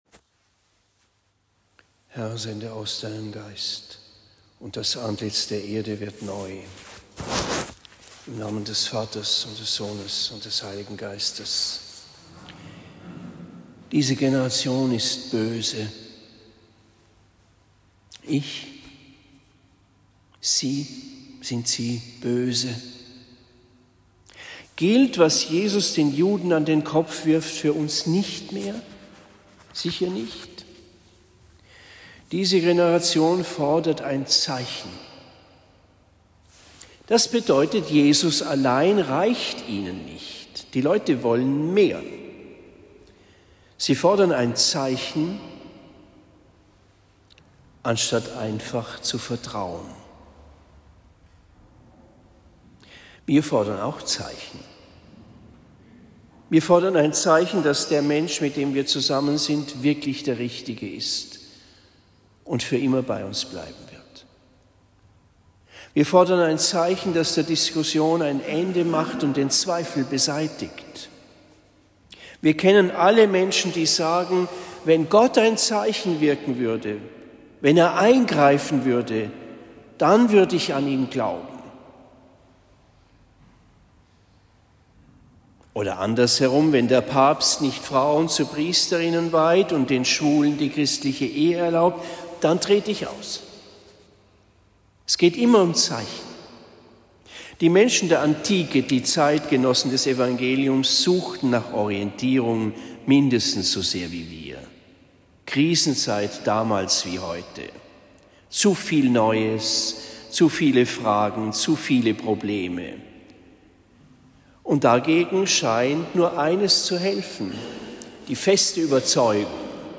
Predigt in Marktheidenfeld St.-Laurentius am 21. Februar 2024